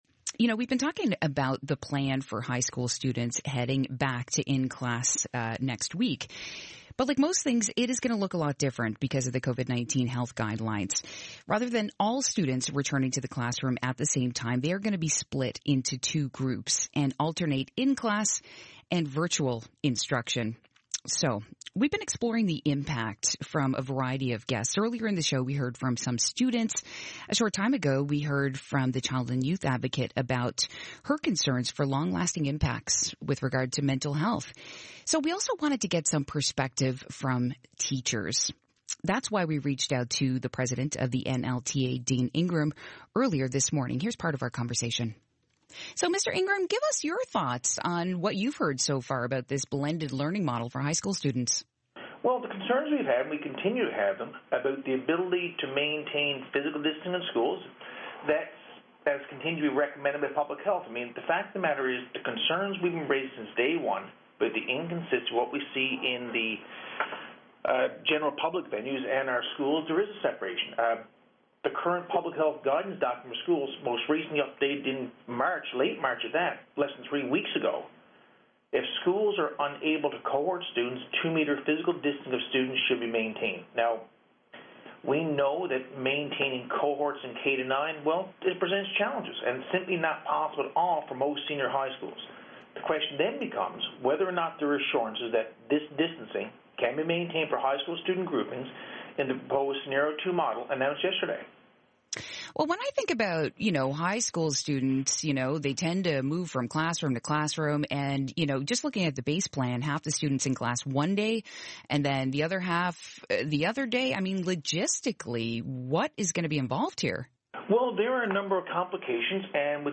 Media Interview - CBC East Coast Morning Show - Apr 8, 2021